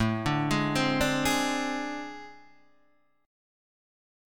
AmM7bb5 chord {5 3 6 5 3 5} chord